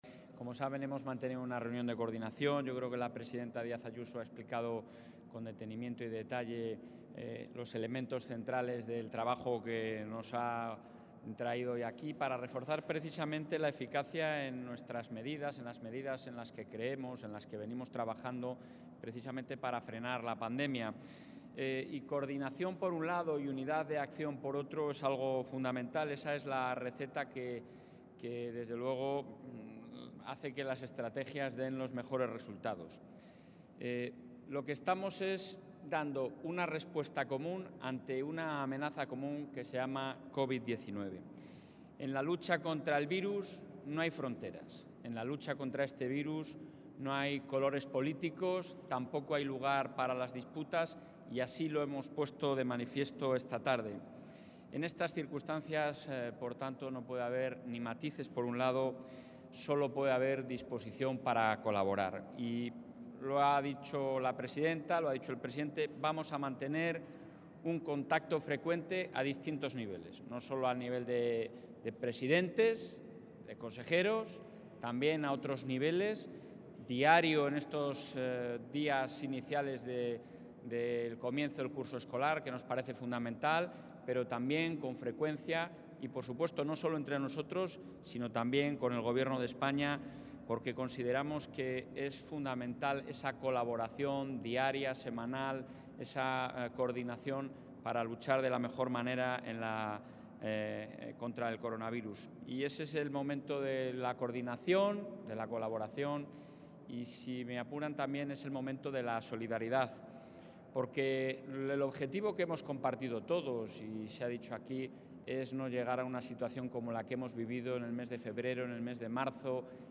Intervención del presidente de la Junta de Castilla y León.